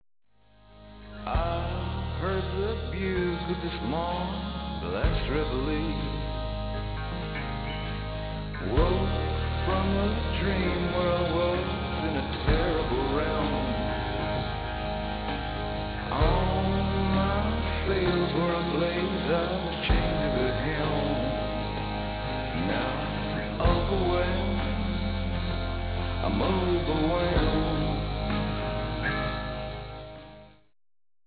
bass, piano, pump organ, electric organ, vocals
cello
pedal steel